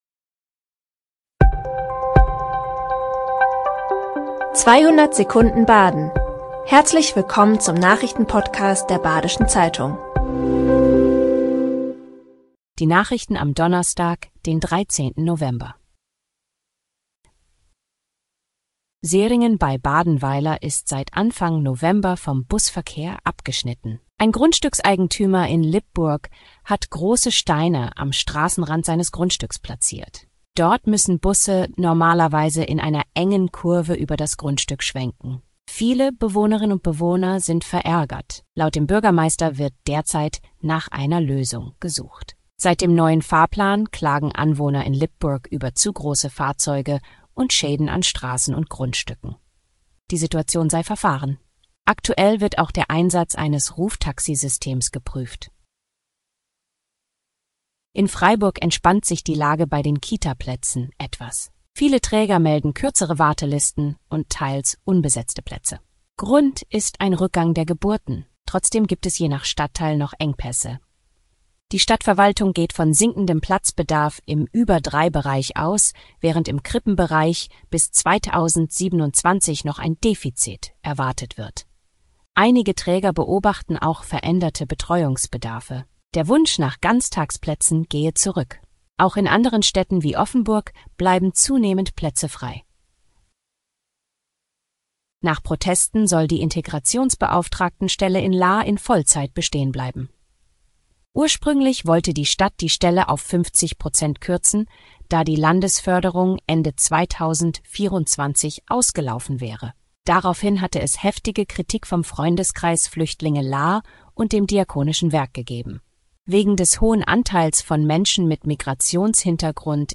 5 Nachrichten in 200 Sekunden.
Nachrichten